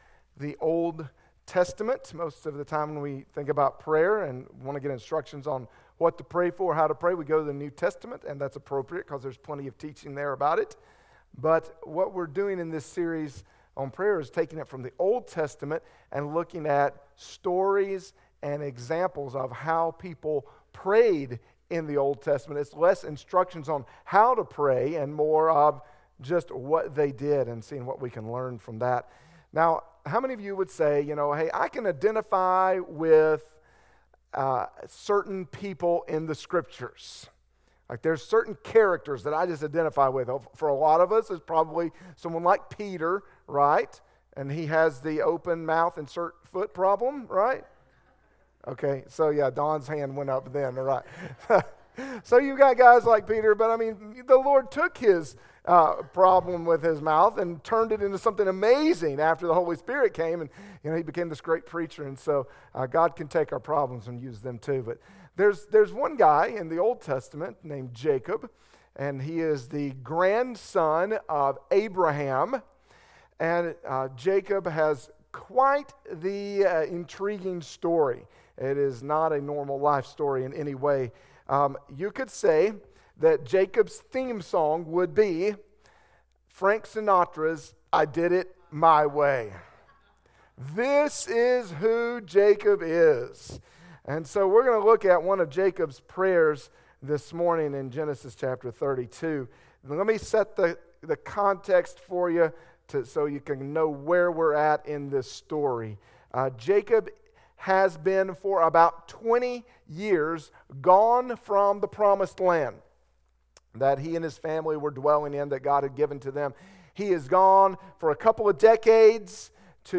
BBC Sermon Audio - Bethlehem Baptist Church